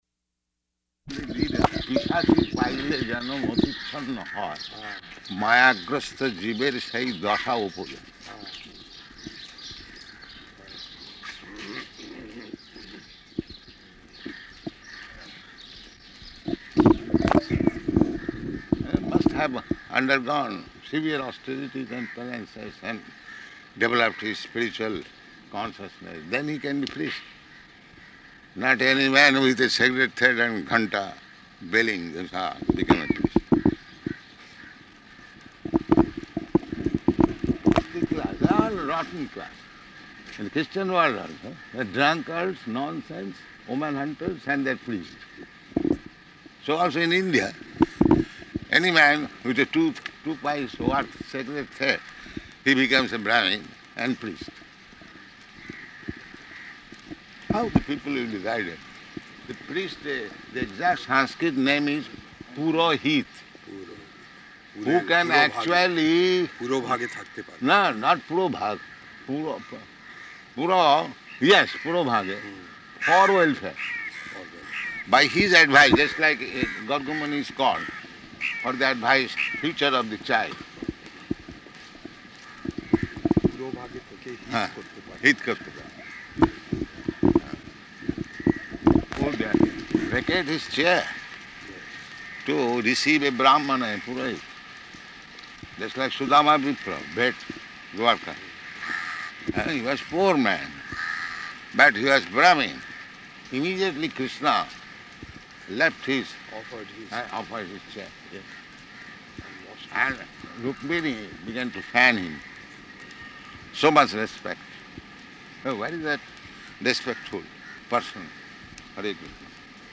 Type: Walk
Location: Bombay